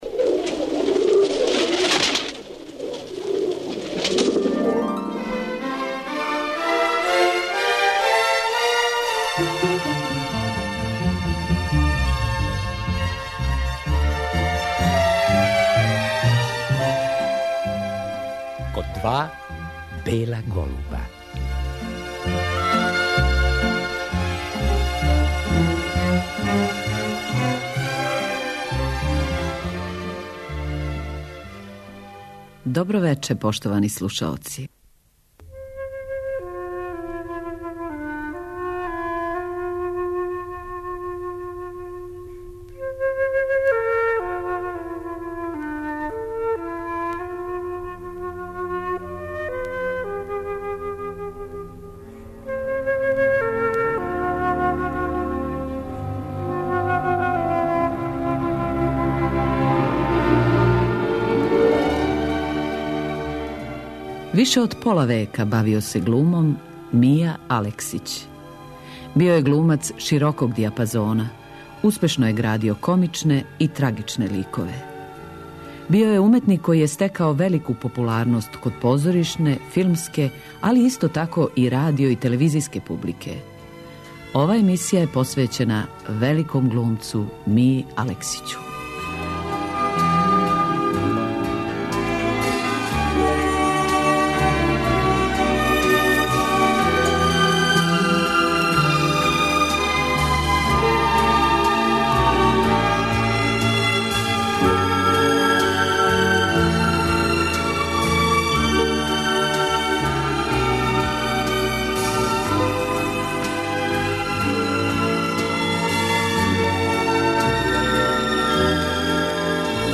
Гост емисије био је 1993. године, некако одамах по завршетку фила"Танго аргетино" у којем је остварио своју последњу улогу. Емисију посвећујемо сећању на тог великог глумца.